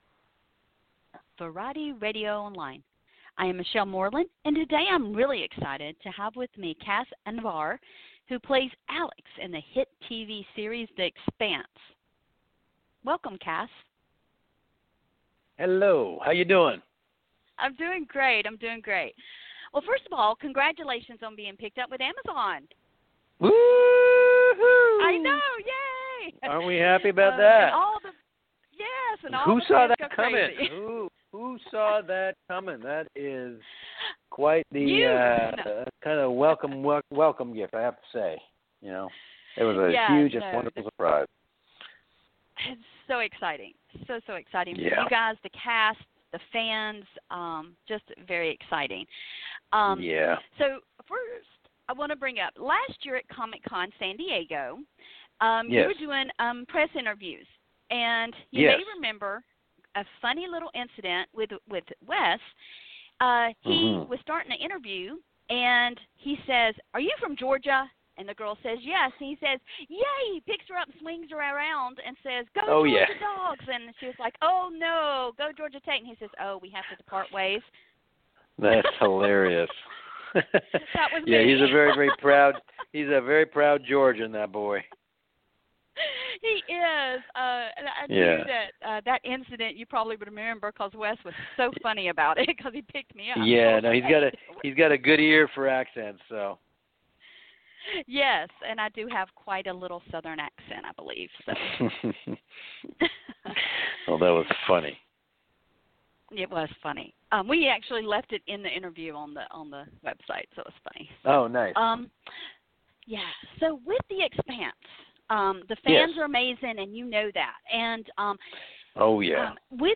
Cas Anvar 'The Expanse' Interview